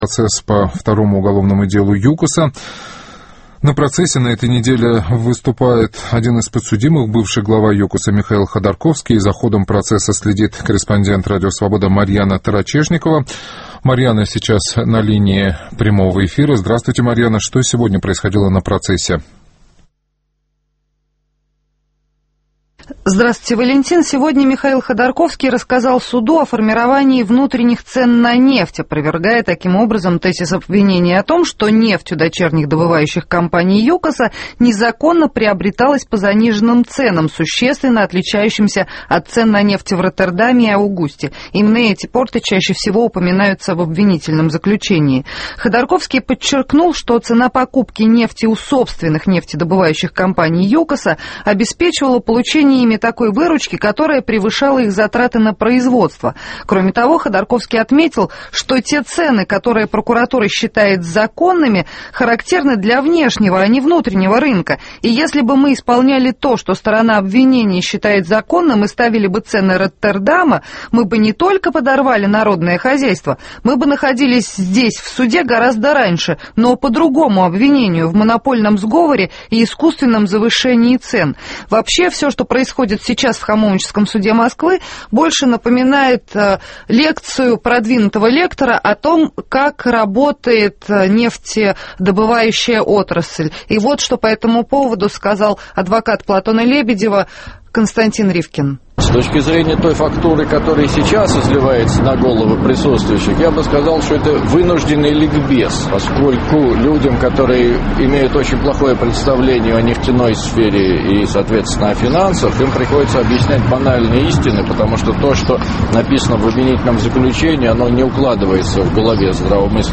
Ходорковский выступил с лекцией про нефть